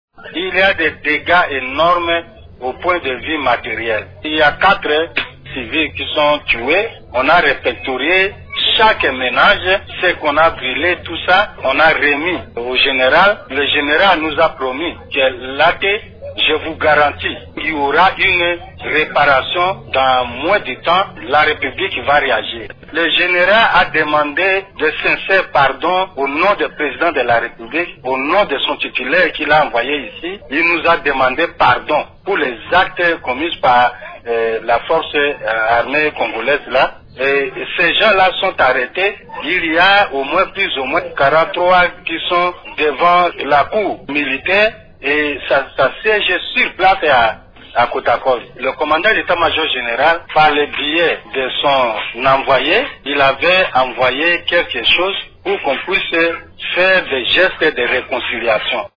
Roger Bangagbia Sese, l’administrateur du territoire de Mobayi-Mbongo auquel appartient Kota-Koli, a accompagné ces autorités militaires sur place.